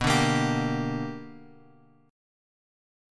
B Minor Major 7th Sharp 5th